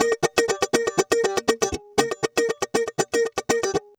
120FUNKY01.wav